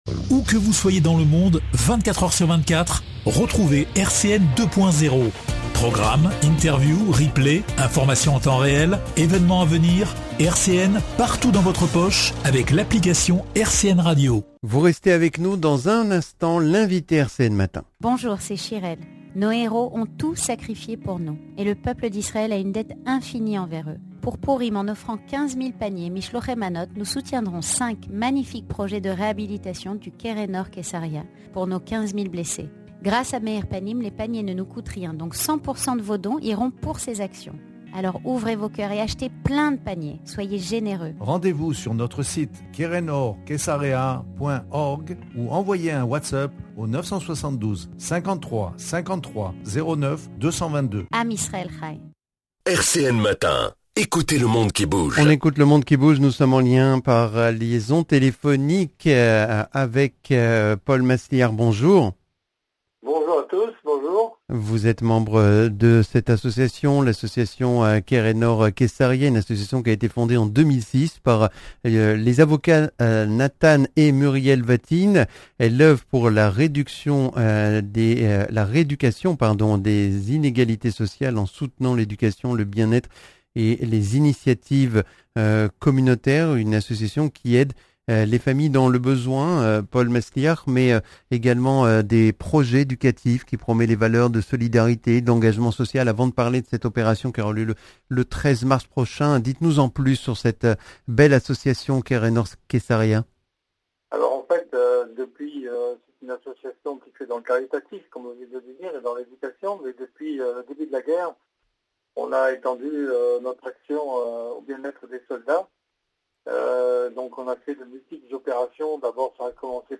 Ecoutez le monde qui bouge Interviews